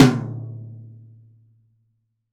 Index of /90_sSampleCDs/AKAI S6000 CD-ROM - Volume 3/Drum_Kit/ROCK_KIT2
T TOM M 1B-S.WAV